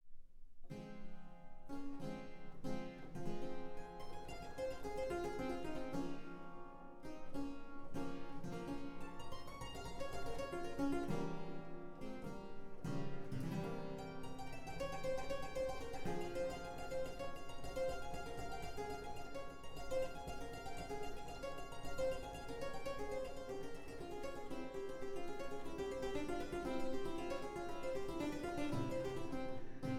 Clavichord